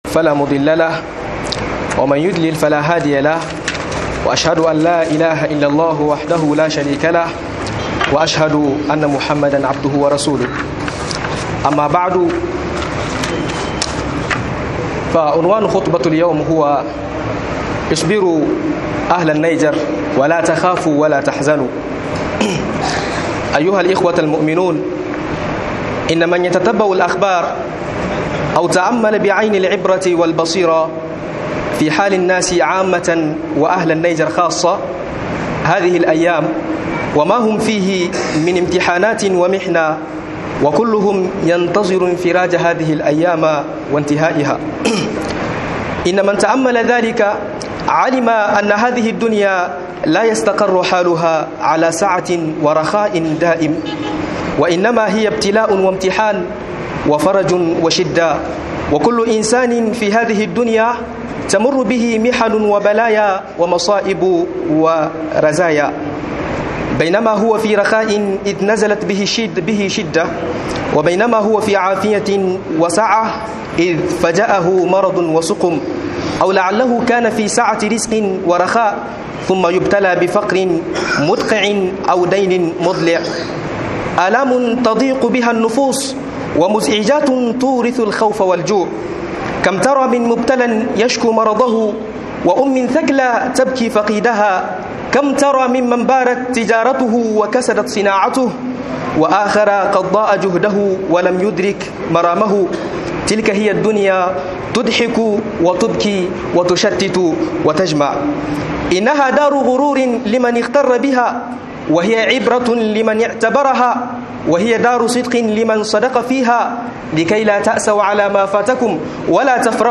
Kuyi hakuri yaku Yan Niger - MUHADARA